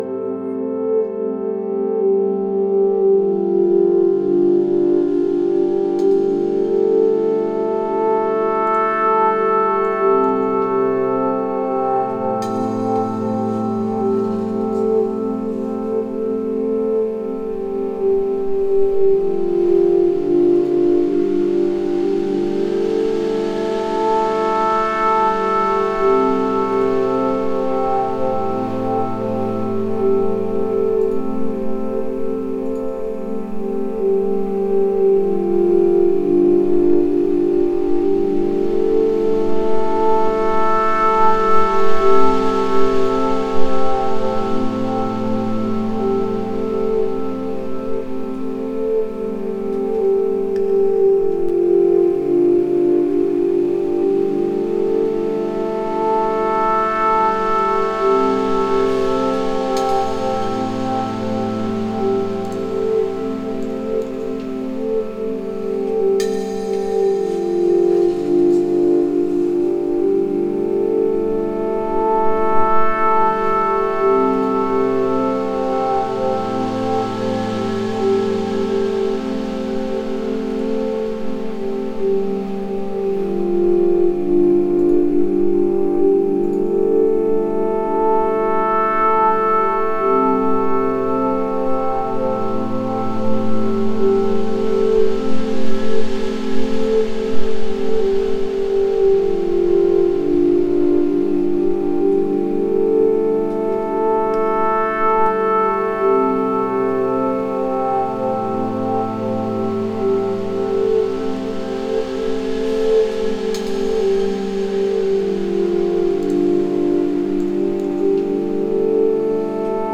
Genre: Ambient, New Age, Meditative.